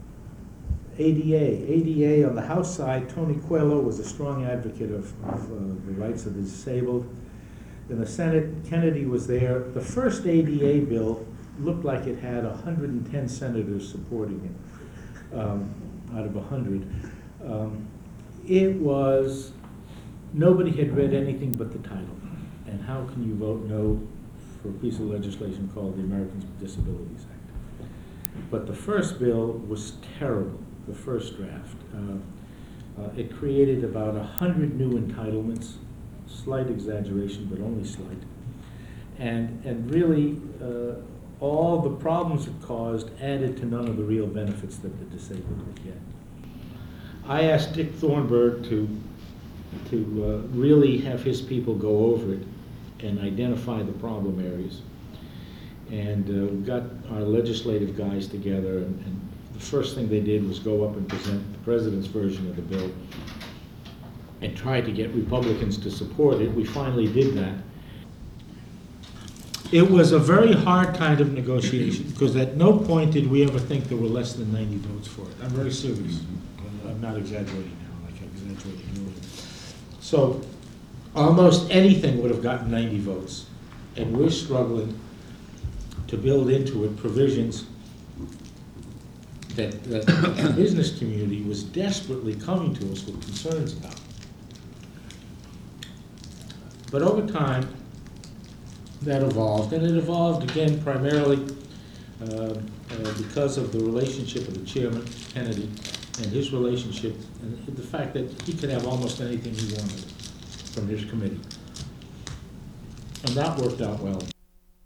'A Hard Kind of Negotiation' Photo: George H.W. Bush Presidential Library and Museum President George H. W. Bush’s chief of staff John H. Sununu recalls working with Congress to pass the Americans with Disabilities Act of 1990. Sununu describes the challenges faced and the relationships that helped pass the legislation. Date: June 8, 2000 Participants John H. Sununu Associated Resources John H. Sununu Oral History (06/2000) John H. Sununu Oral History (11/2000) The George H. W. Bush Presidential Oral History Audio File Transcript